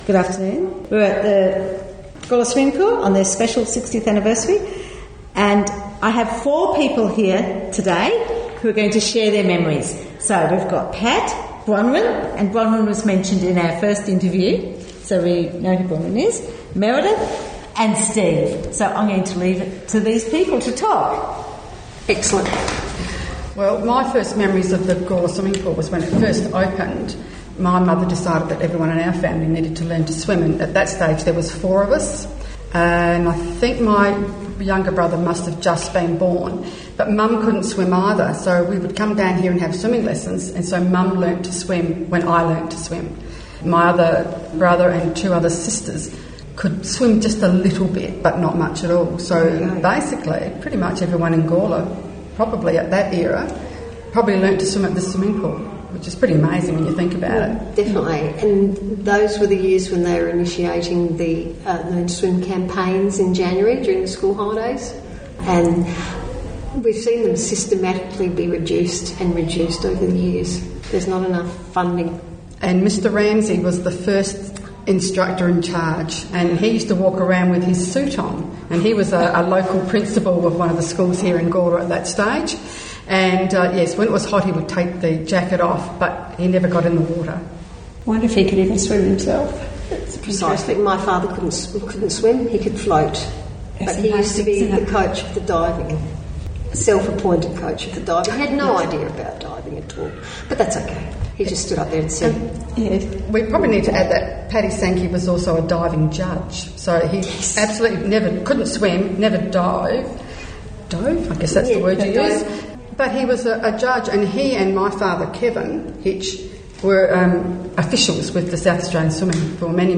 Gawler Swimming Pool: Oral Histories
Some of those who have been instrumental to the pool’s history share memories about this beloved local institution.